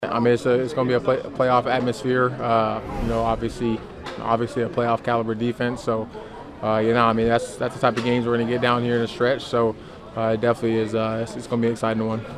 Packers wide receiver Christian Watson on this matchup having a playoff atmosphere.